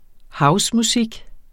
Udtale [ ˈhɑws- ]